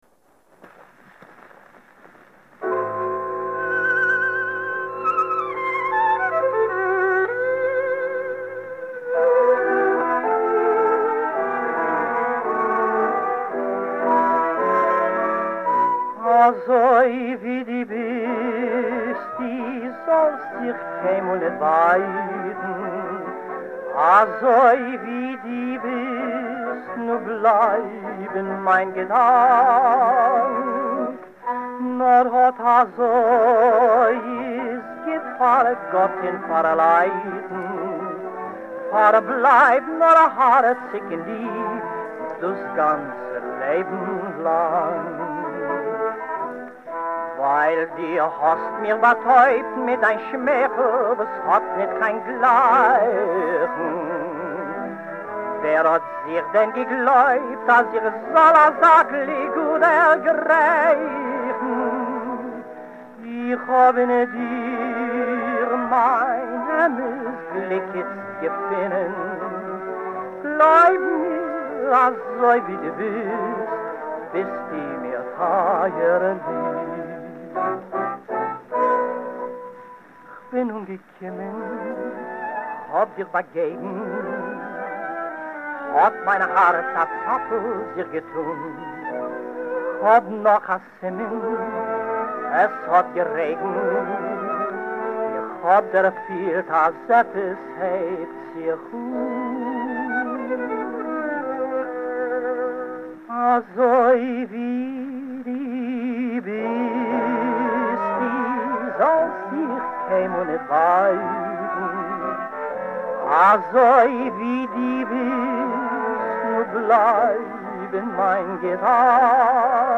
American recordings of songs in Yiddish
clarinet